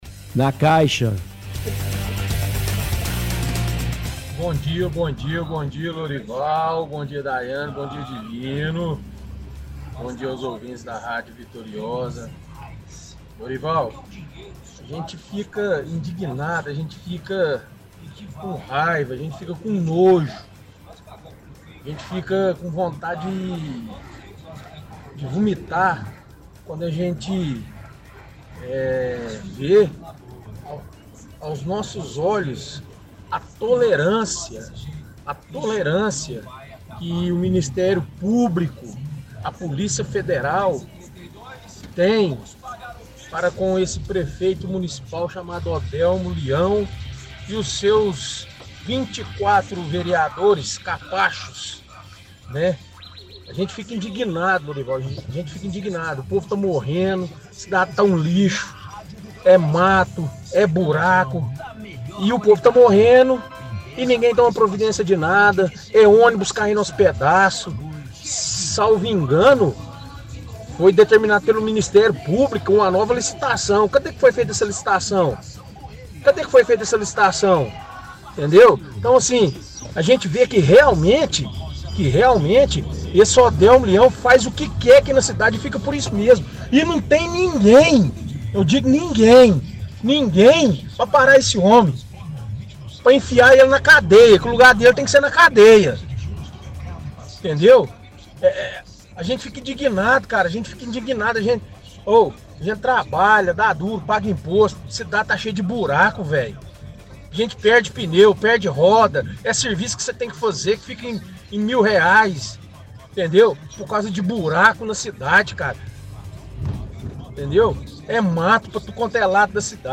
– Ouvinte reclama que a cidade está cheia de mato, buraco, fala que a cidade está um ‘lixo’ os ônibus estão caindo os pedaços.